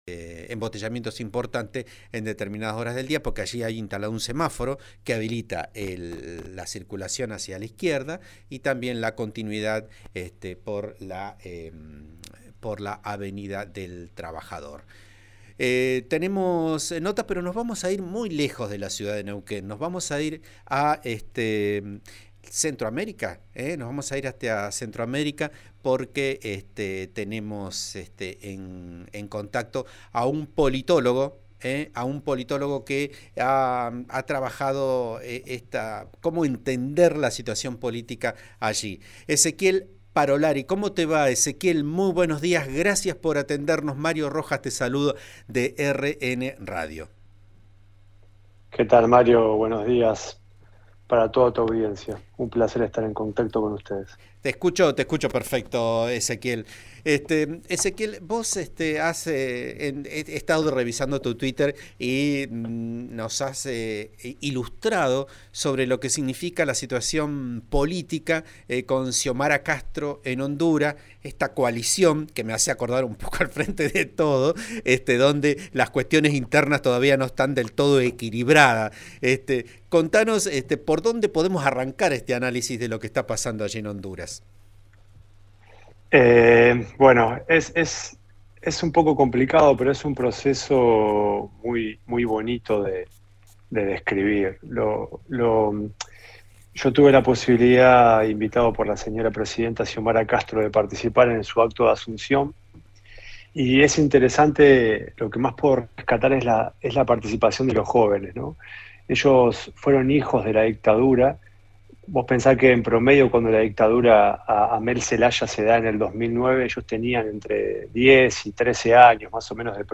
La mandataria juró en su nuevo cargo en un acto en el Estadio Nacional de Tegucigalpa con la presencia de Cristina Kirchner.
Xiomara Castro asumió hoy como presidenta de Honduras, la primera mujer en llegar a ese cargo, en un acto desarrollado en el Estadio Nacional de Tegucigalpa, que estuvo colmado por sus seguidores y con el primer desafío de apagar una crisis en el Parlamento que ya amenaza su liderazgo.